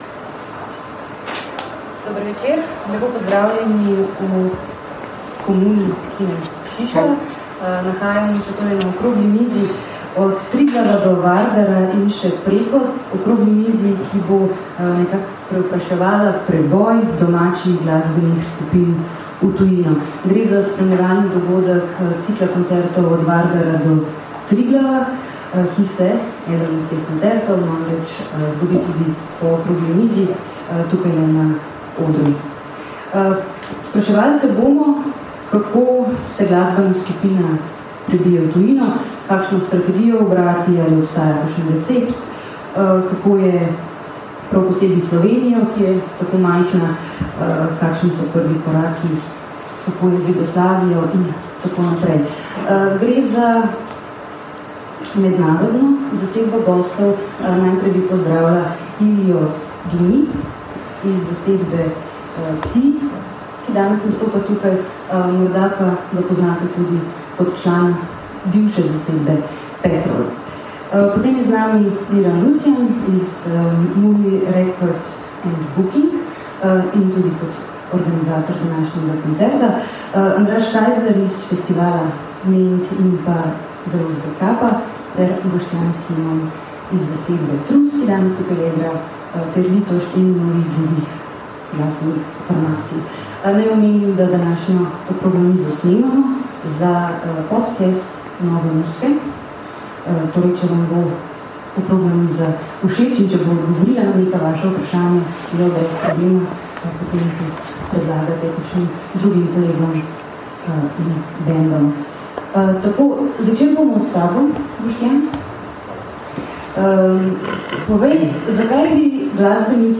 Posnetek okrogle mize o preboju domačih glasbenih skupin v tujino , ki je potekala v četrtek, 23. oktobra 2014 v Komuni ljubljanskega Kina Šiška v okviru koncertnega večera NuYuGo! – Glasbeni maraton trojk od Vardara do Triglava!